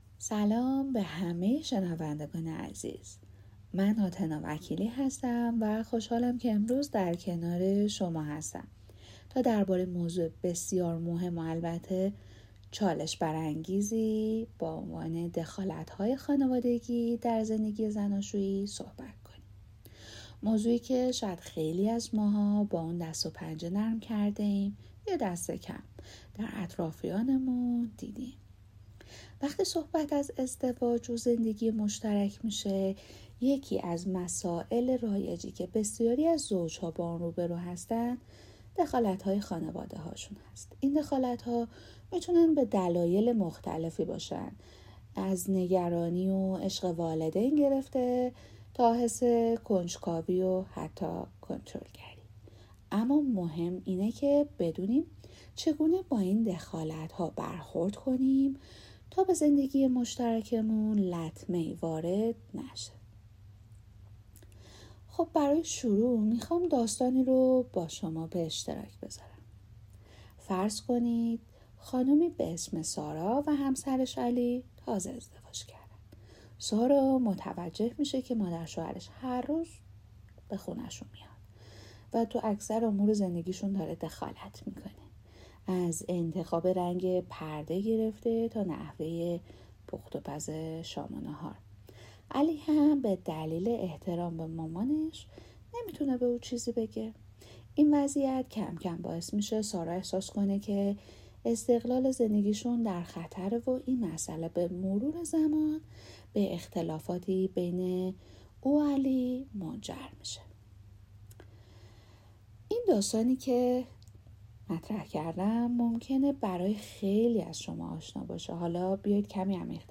از زبان متخصص: